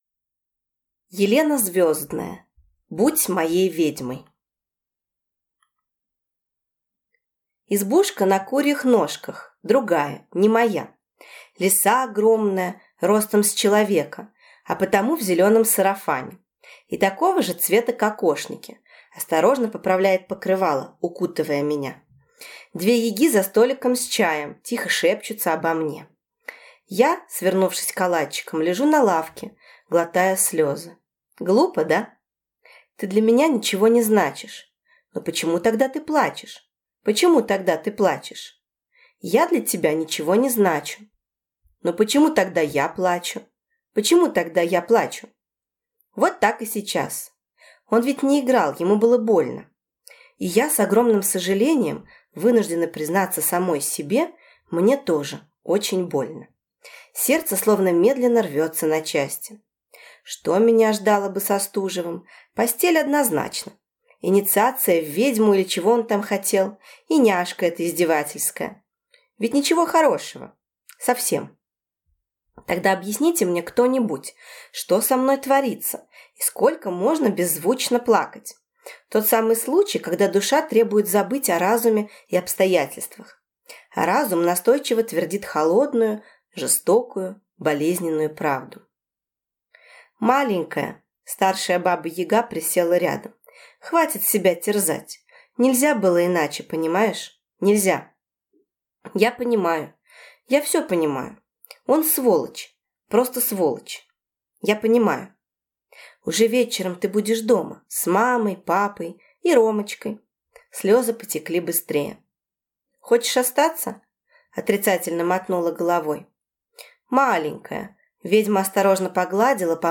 Аудиокнига Будь моей ведьмой | Библиотека аудиокниг